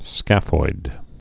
(skăfoid)